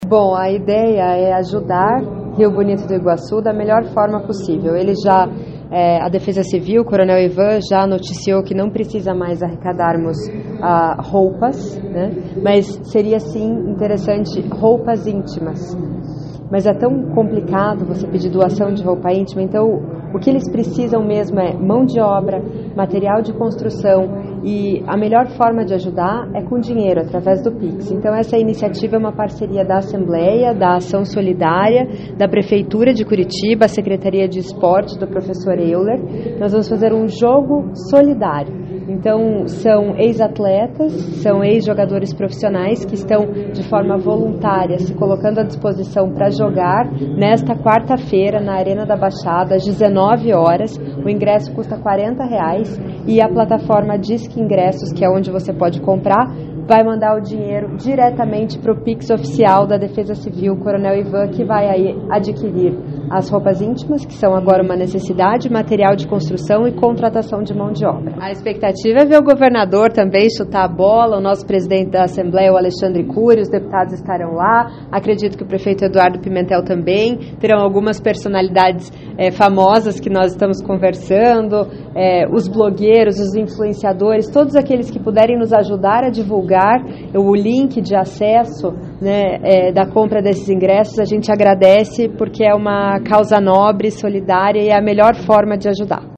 Ouça o que diz a presidente do Conselho, deputada Maria Victória: